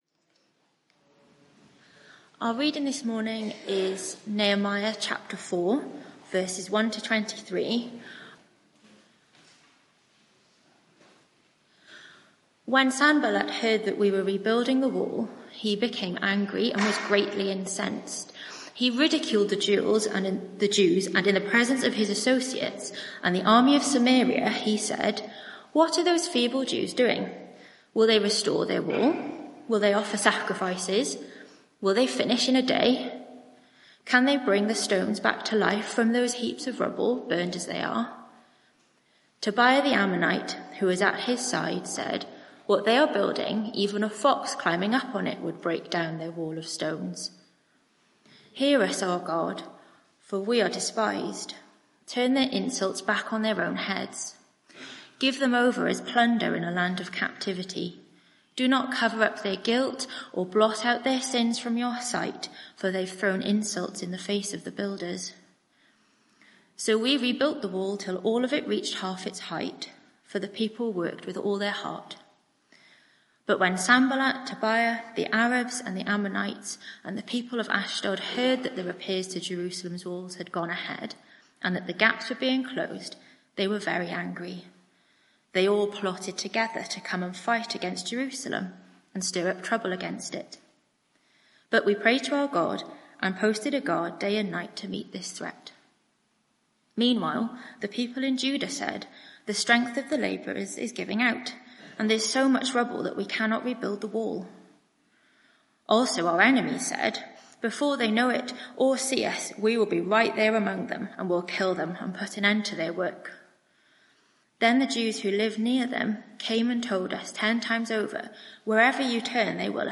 Media for 11am Service on Sun 28th Apr 2024 11:00 Speaker